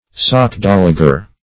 Sockdolager \Sock*dol"a*ger\, n. [A corruption of doxology.]